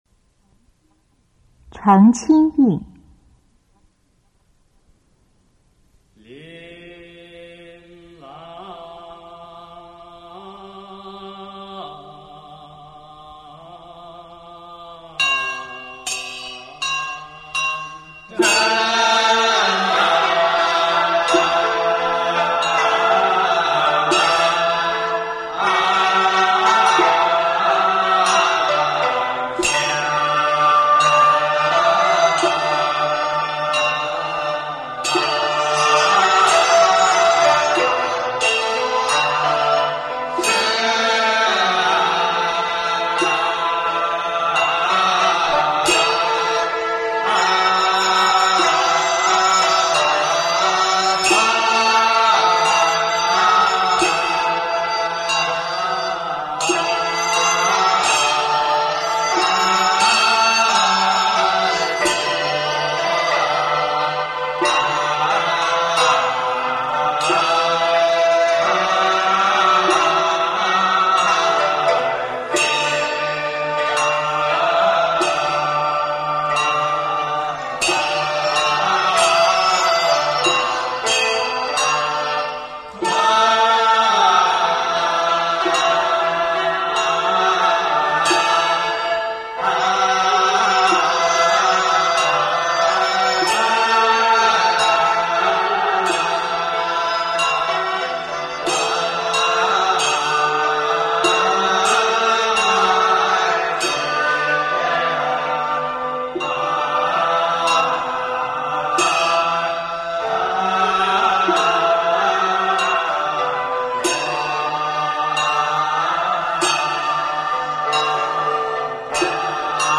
中国道教音乐 全真正韵 澄清韵 - 道音文化
此曲在坛中具有导引信徒宁静性灵的功能，亦且有荡秽除邪之作用。属阳韵。